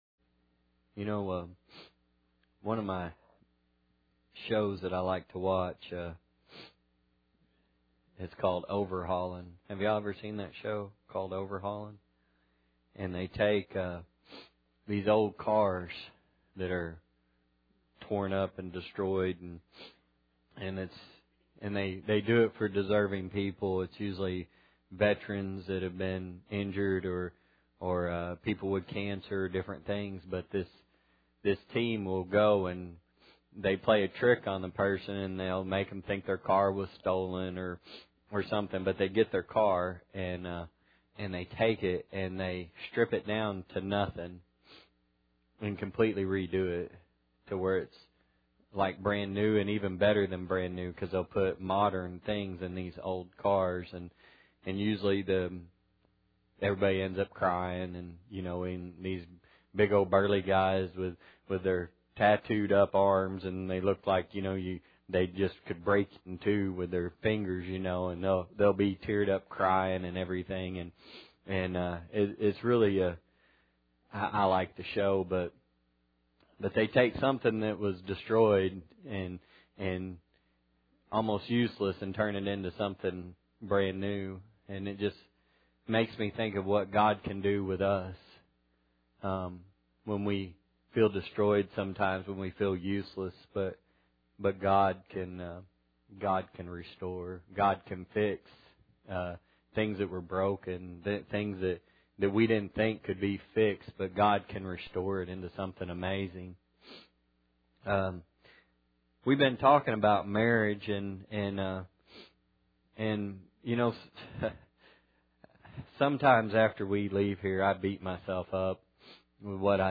Service Type: Sunday Night